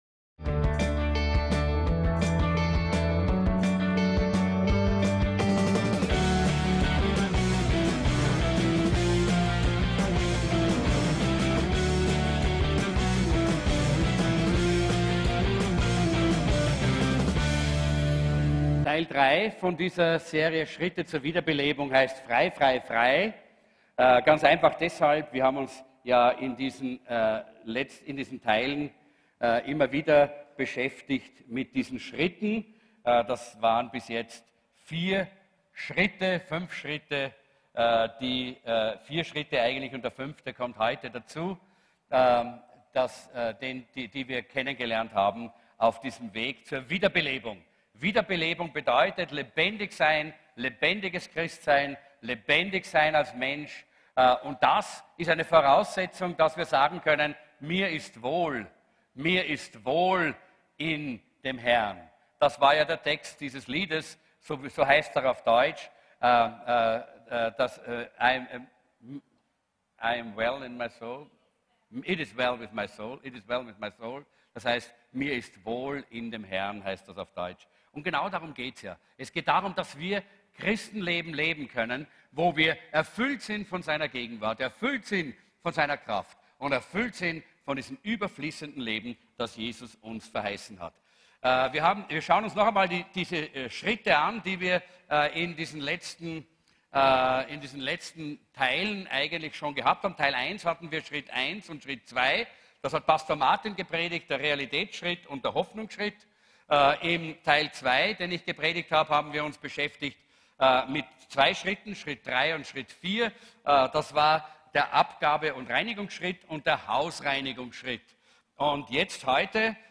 FREI FREI FREI ~ VCC JesusZentrum Gottesdienste (audio)